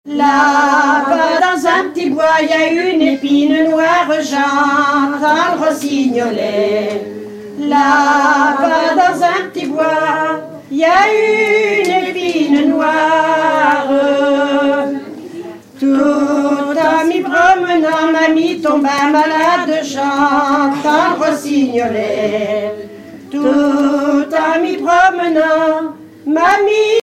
Genre strophique
10 ans de fêtes du chant à Bovel